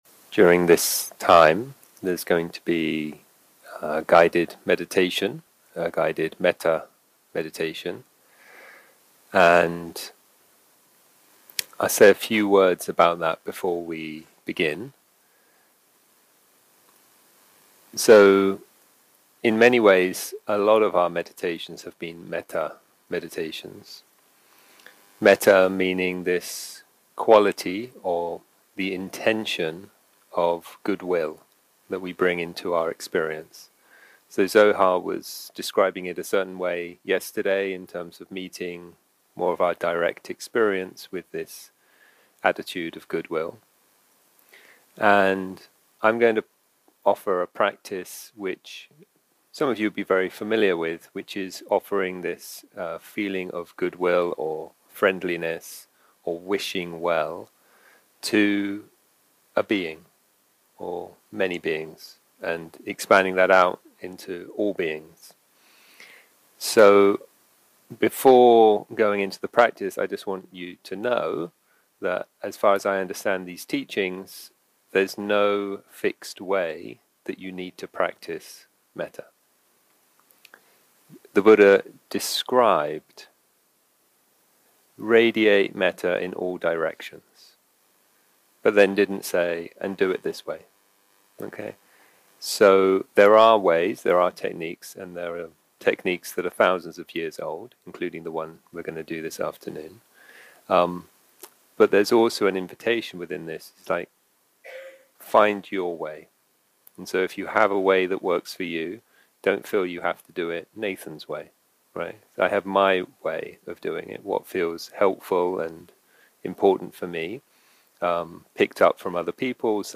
יום 3 – הקלטה 6 – צהריים – מדיטציה מונחית – Cultivating Metta
יום 3 – הקלטה 6 – צהריים – מדיטציה מונחית – Cultivating Metta 0:00 0:00 סוג ההקלטה: Dharma type: Guided meditation שפת ההקלטה: Dharma talk language: English